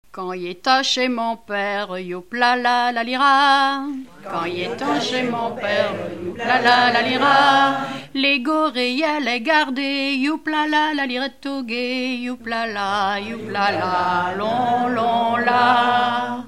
Genre laisse
Enquête Arexcpo en Vendée-Pays Sud-Vendée
Catégorie Pièce musicale inédite